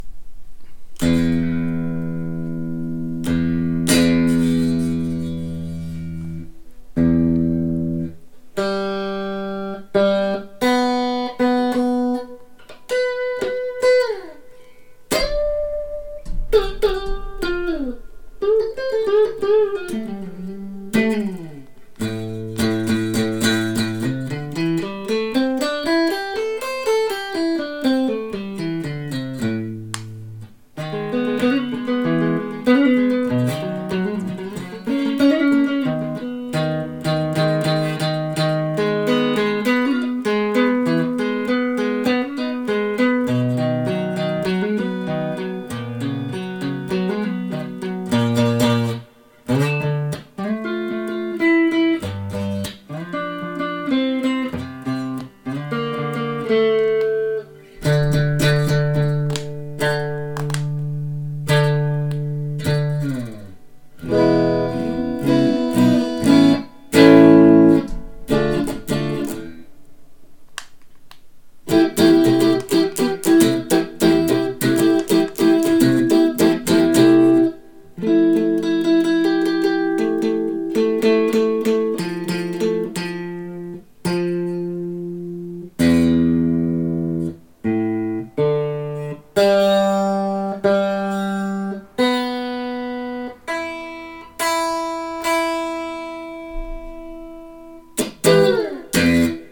--- Beiträge wurden zusammengefasst --- Hier mal ein Soundbeispiel von dem Schnarrsound, ich hör ihn beim Greifen an der Stelle wo ich greife und bei offenen Saiten entweder oben am Sattel oder unten an der Bridge (es klirrt so hell bei der G-Saite) Anhänge sound demo.mp3 1,1 MB · Aufrufe: 250